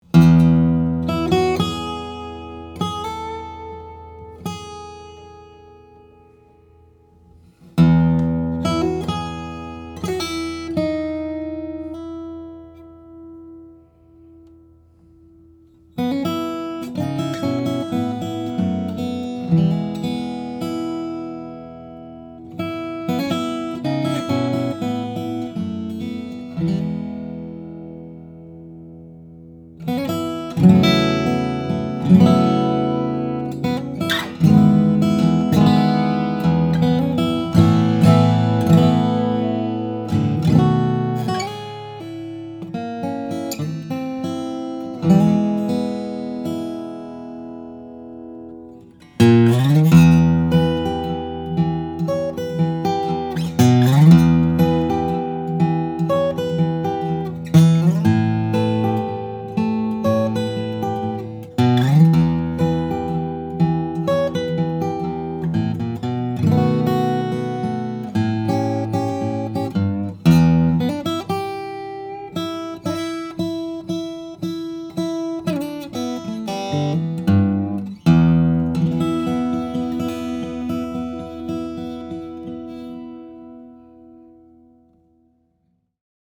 And this 2013 RJN-5C is a real, true, and incredibly expressive instrument.